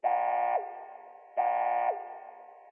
foKlaxonA.ogg